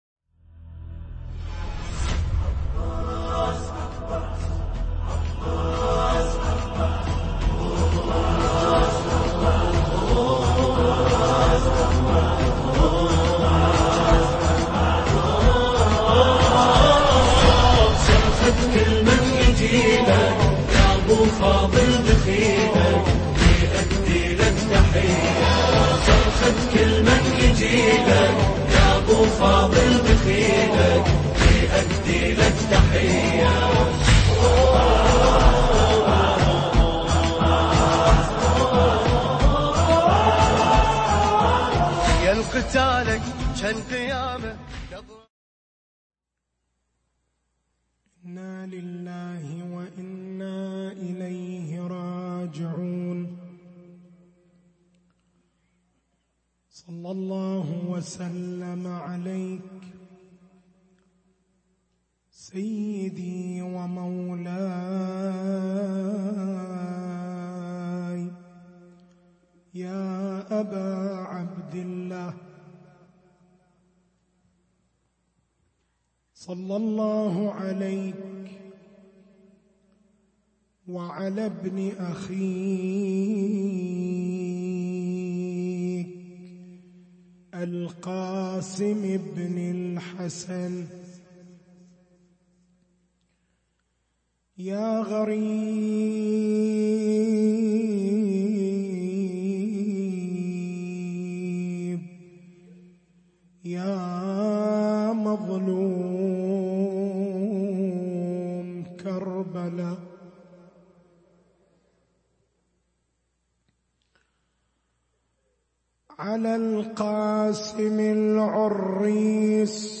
تاريخ المحاضرة: 08/01/1440